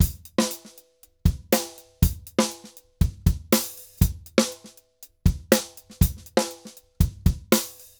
Drums_Candombe 120_2.wav